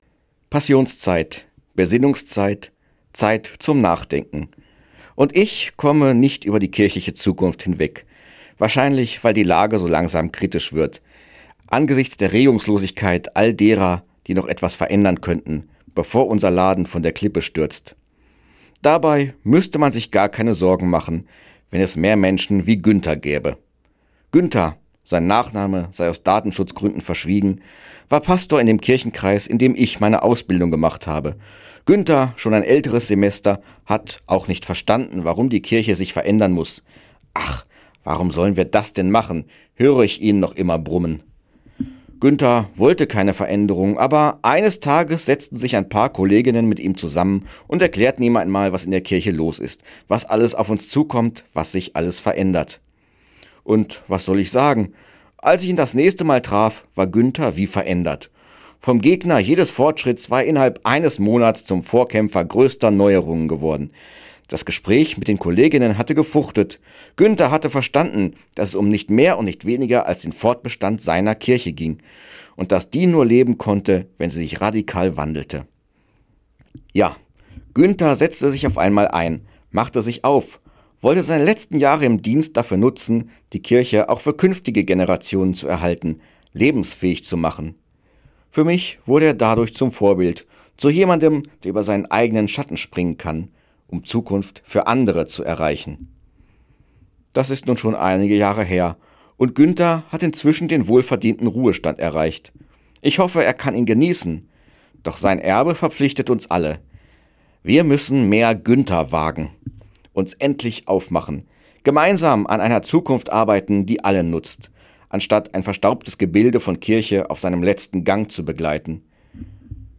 Radioandacht vom 9. April